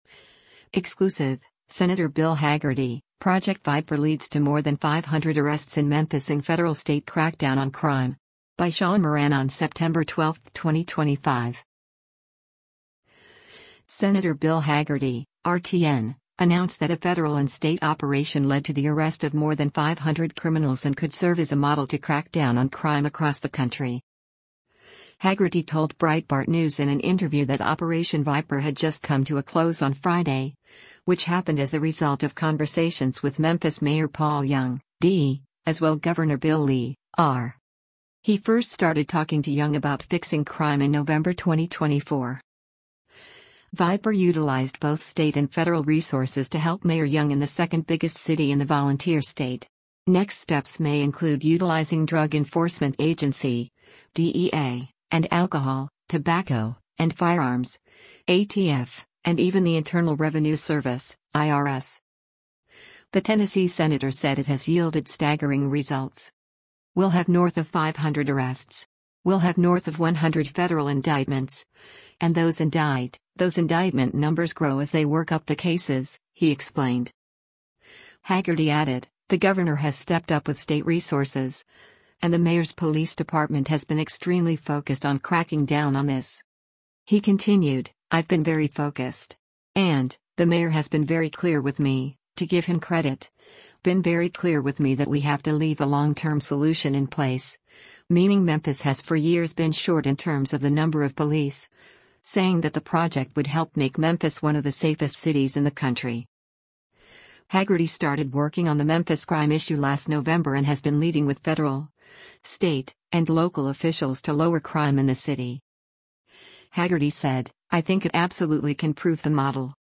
Hagerty told Breitbart News in an interview that Operation Viper had just come to a close on Friday, which happened as the result of conversations with Memphis Mayor Paul Young (D) as well Gov. Bill Lee (R).